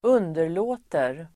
Uttal: [²'un:der_lå:ter]